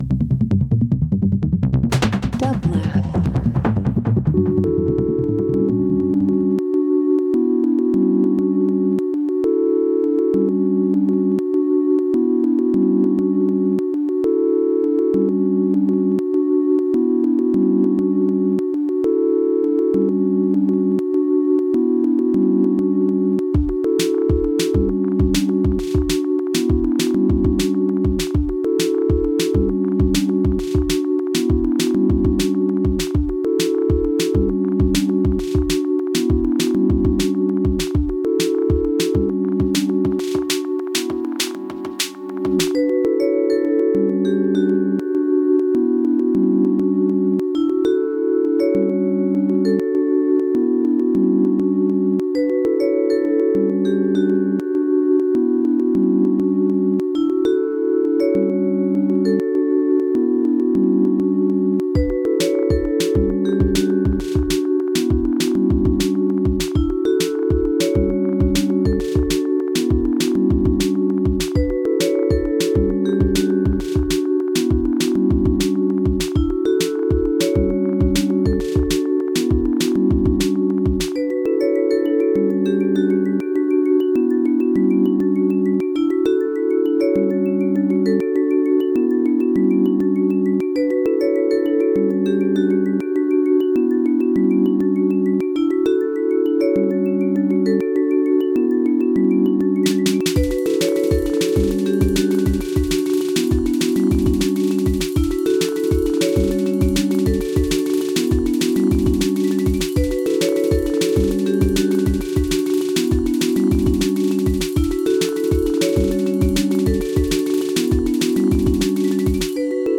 Breaks Dance Footwork Jungle